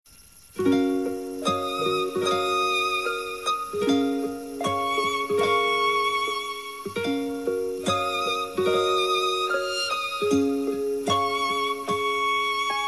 短信铃声